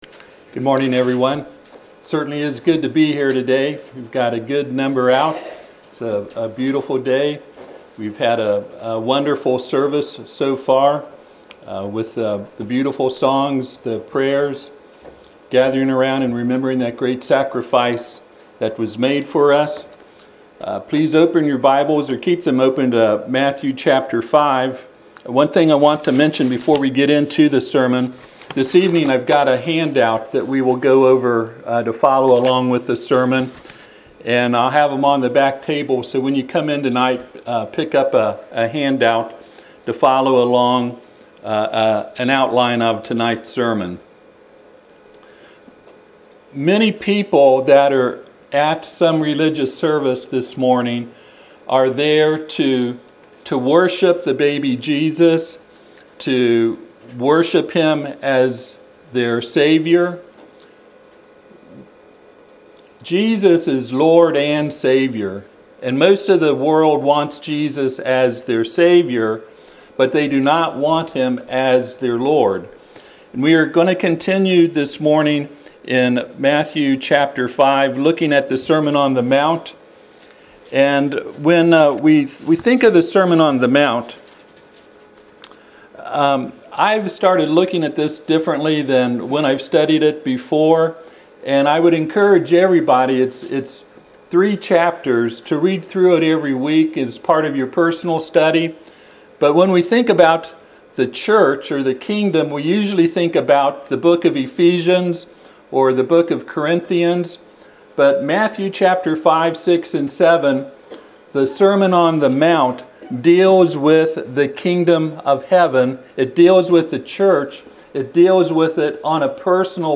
Sermon On The Mount Passage: Matthew 5:17-48 Service Type: Sunday AM Topics